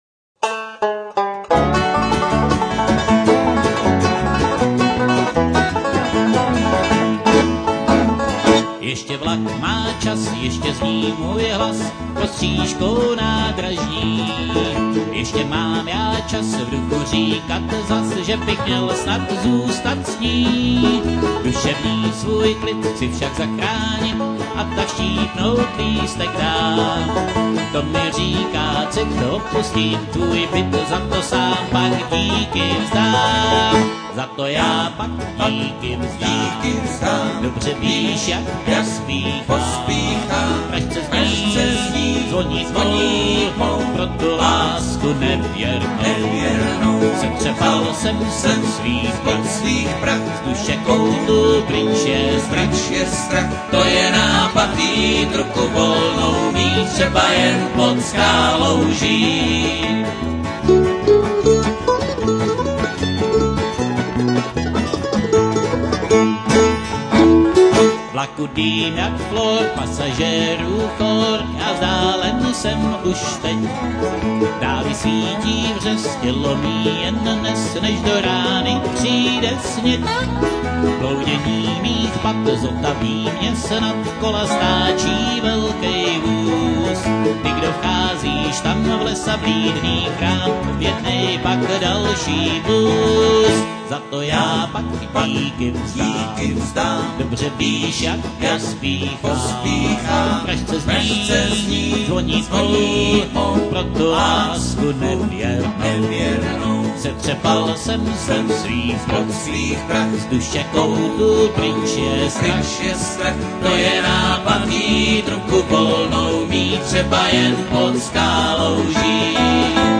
banjo
dobro
mandolin
el.bass